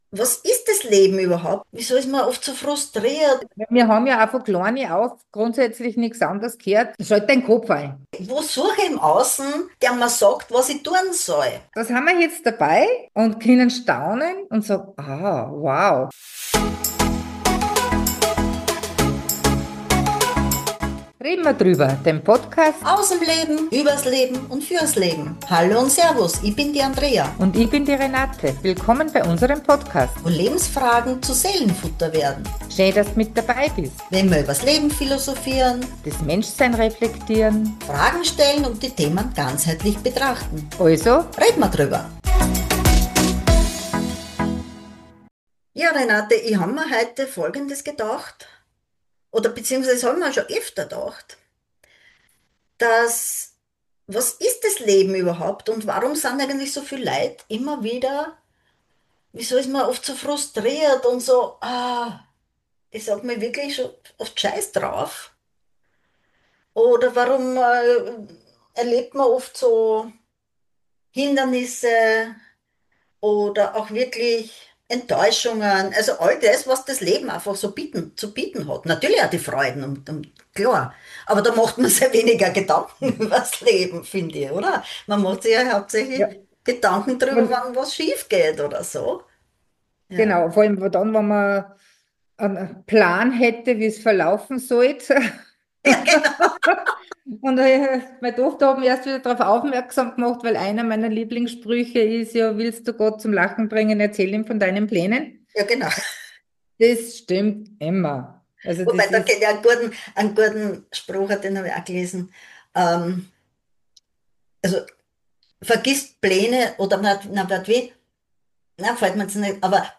Wir nehmen dich mit in ein ehrliches Gespräch über Gedanken, innere Muster und die Suche nach Sinn. Es geht um Dankbarkeit, Bewusstsein und darum, das Leben vielleicht wieder mit neuen Augen zu sehen. Eine ruhige, tiefgehende Folge über das Menschsein – und die Einladung, wieder mehr zu staunen.